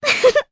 Artemia-Vox_Happy1_jp.wav